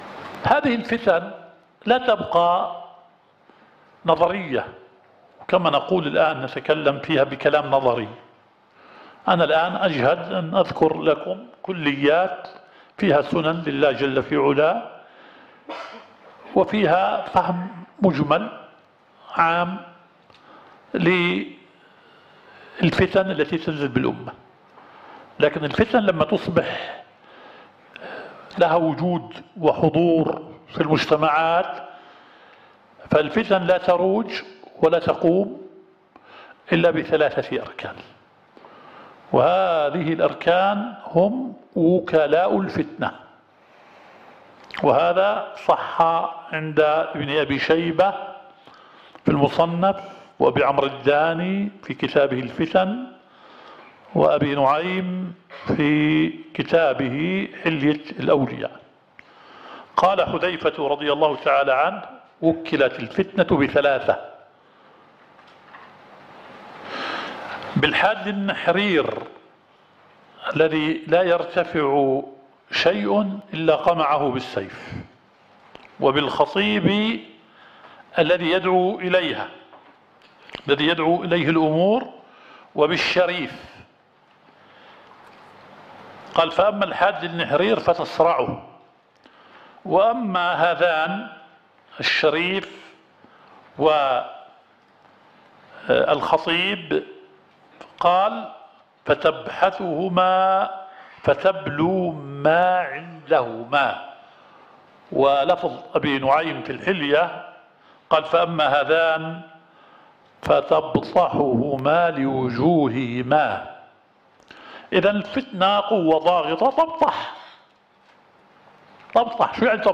الدورة الشرعية الثالثة للدعاة في اندونيسيا – منهج السلف في التعامل مع الفتن – المحاضرة الثانية.